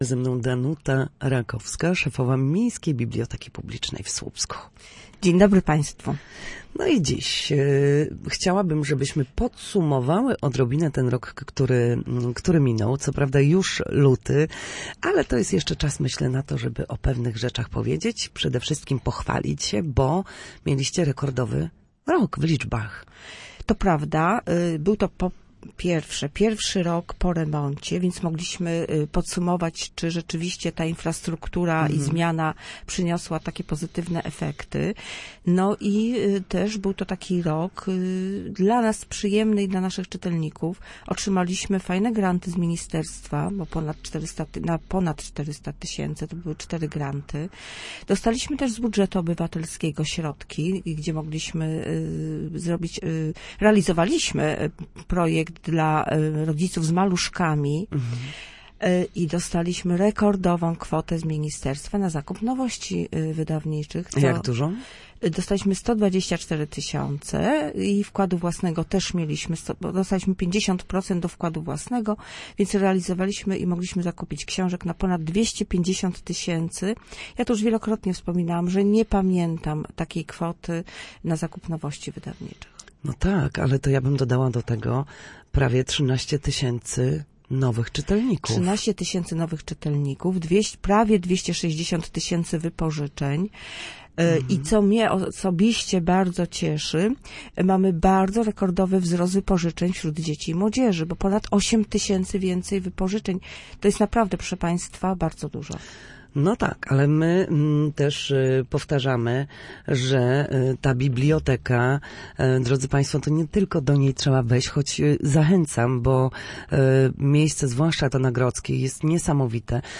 Na naszej antenie podsumowała miniony rok, który okazał się wyjątkowo udany dla słupskiej biblioteki.